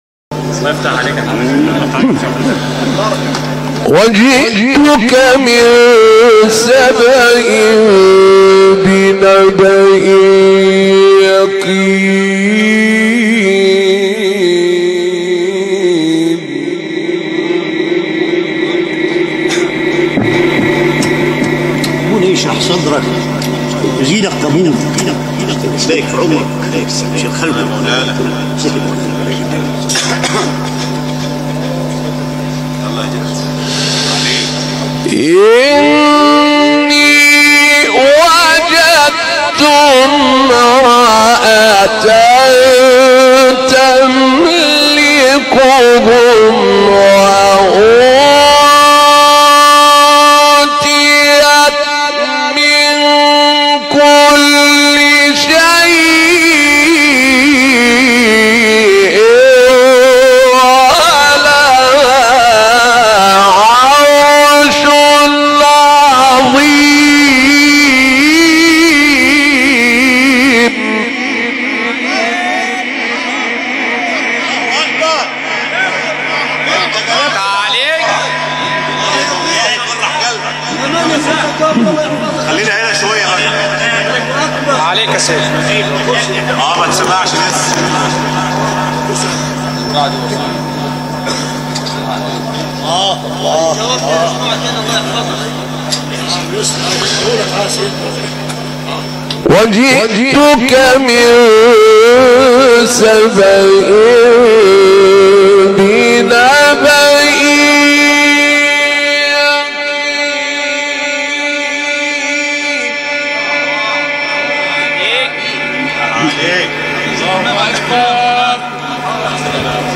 مقطع زیبای استاد عبدالفتاح طاروطی | نغمات قرآن | دانلود تلاوت قرآن